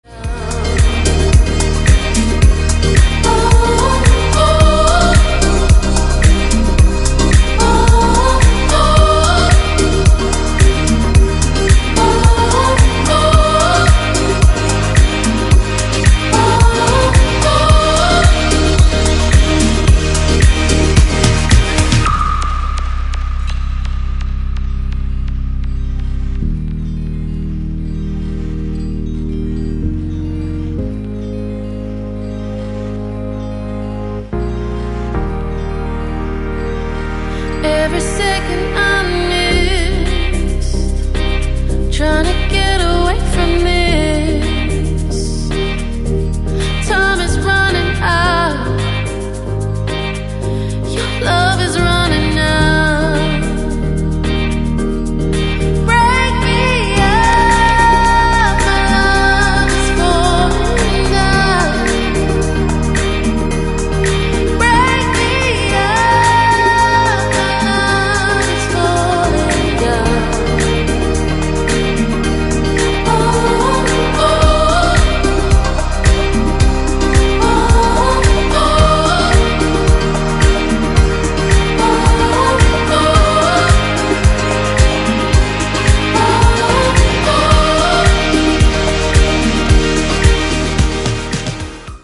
ジャンル(スタイル) NU DISCO / DISCO / HOUSE